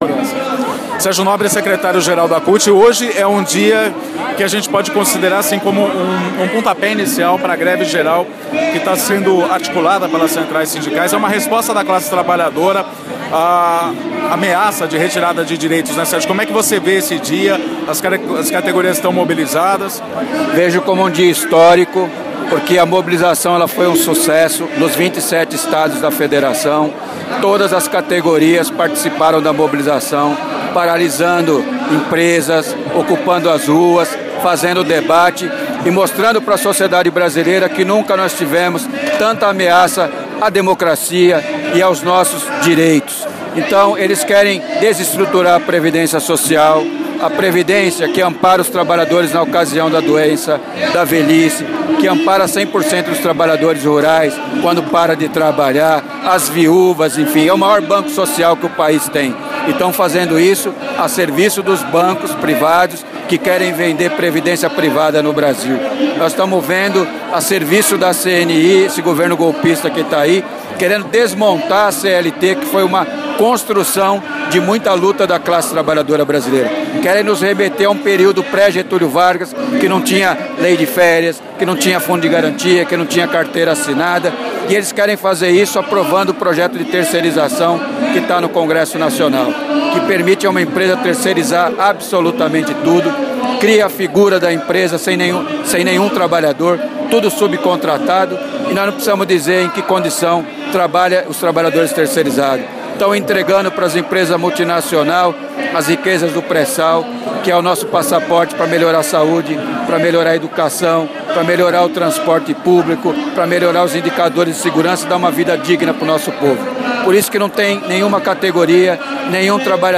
Entrevista com Sérgio Nobre no Dia Nacional de Paralisação e Mobilização das categorias